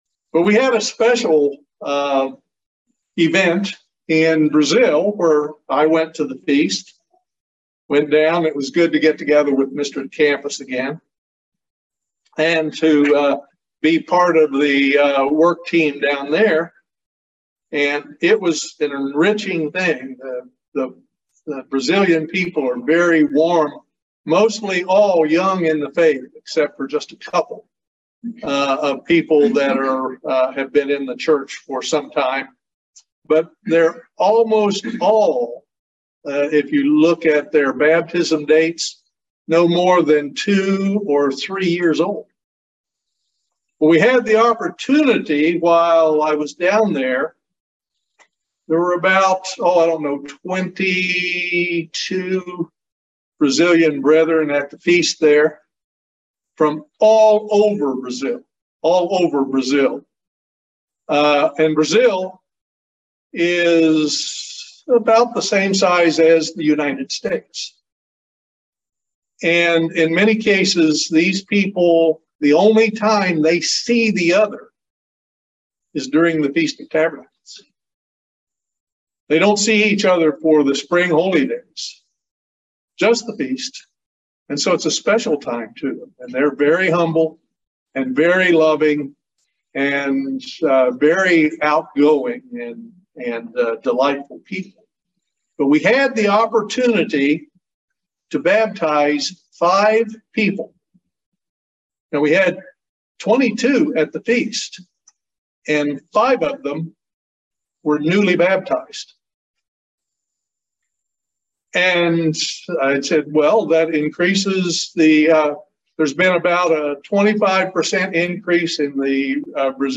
Sermons
Given in Lexington, KY